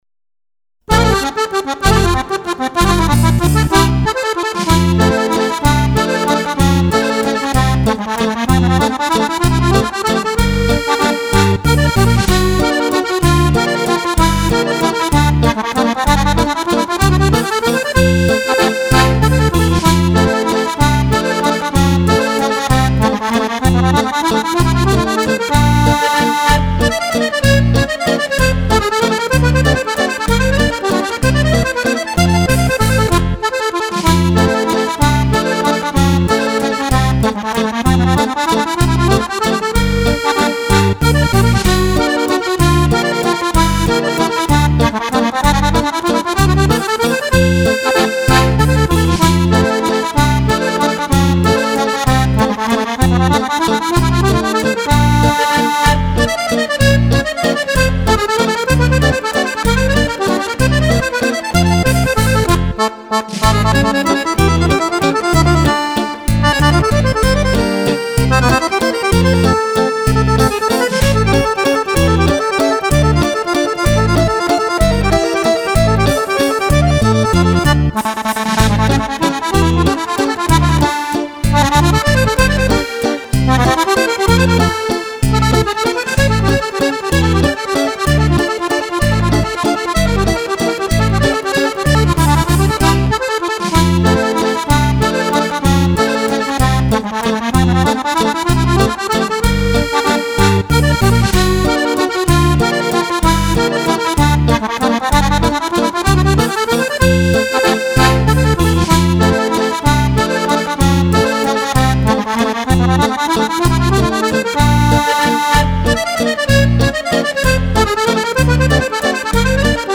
Tre brani per Fisarmonica di grande effetto.
Valzer musette